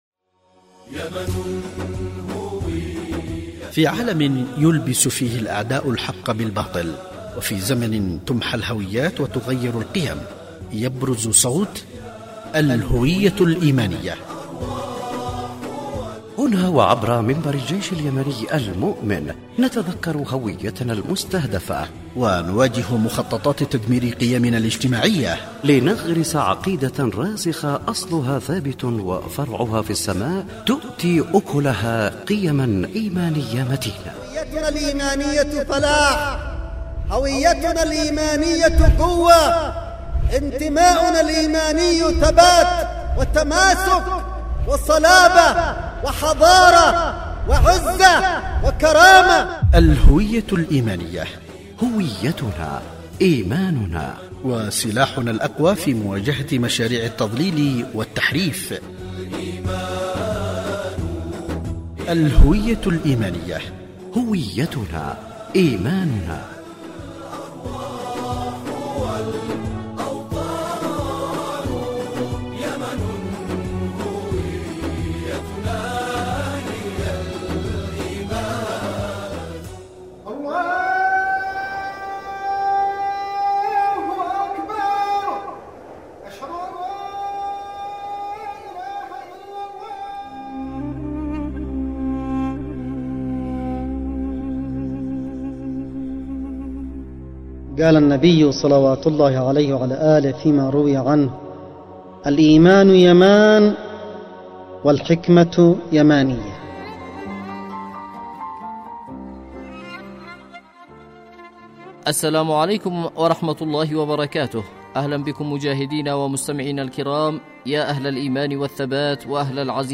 الهوية الإيمانية، برنامج إذاعي يتحدث عن الهوية الايمانية واهم الاعمال والبرامج التي يعمل عليها لطمس وتغيير الهوية وكيف نواجه مخططات الأعداء